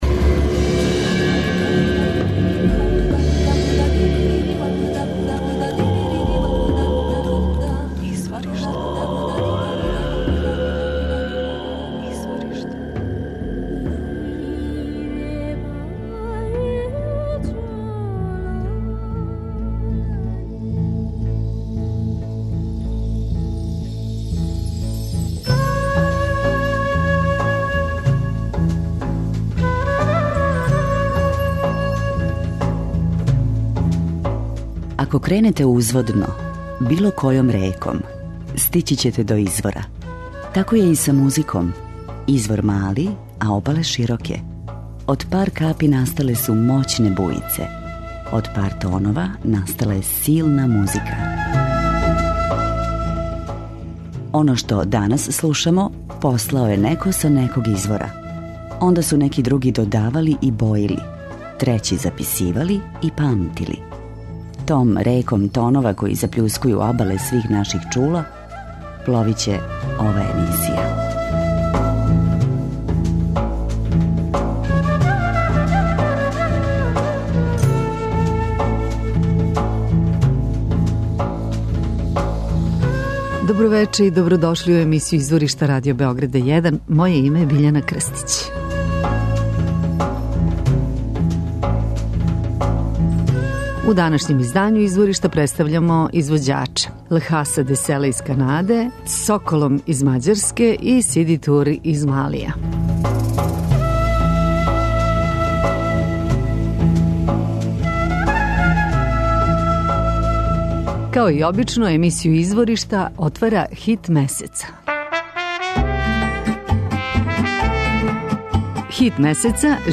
songhai blues